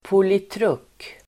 Ladda ner uttalet
Uttal: [politr'uk:]
politruk.mp3